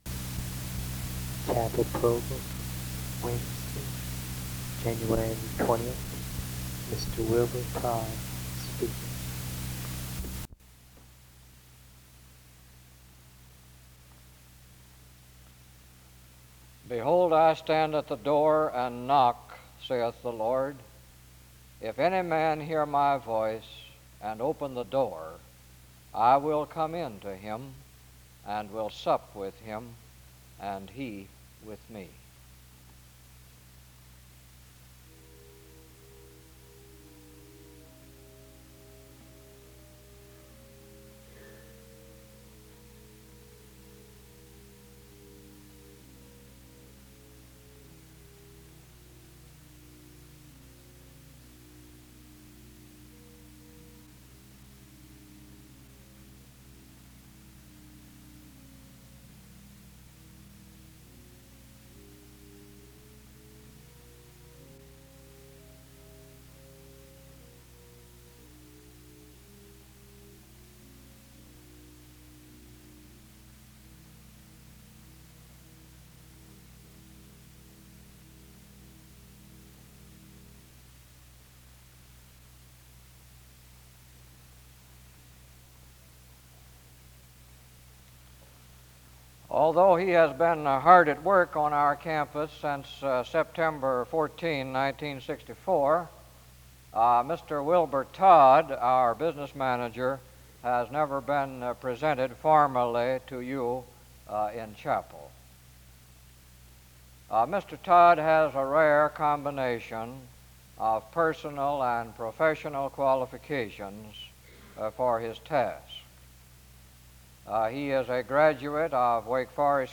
A responsive reading takes place from 7:00-8:55. A prayer is offered from 8:57-11:24.